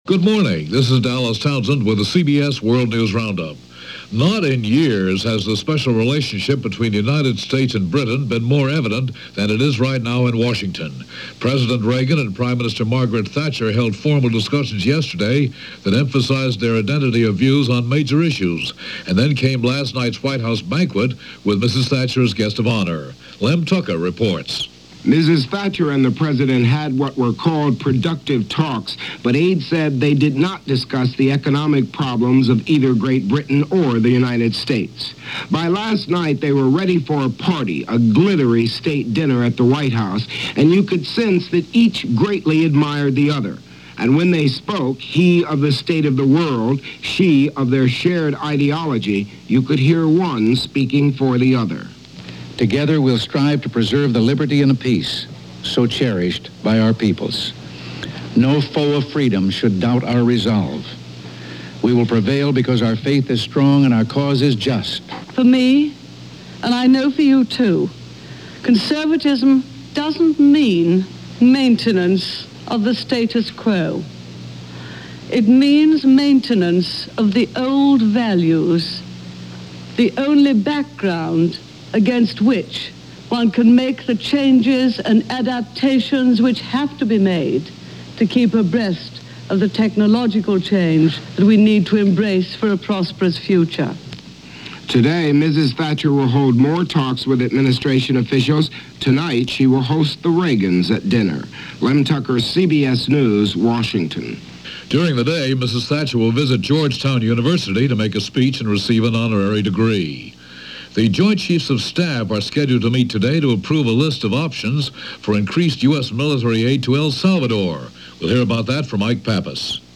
Reagan -Thatcher Summit: Love Fest - February 27, 1981 - news for this day in 1981 presented by The CBS World News Roundup.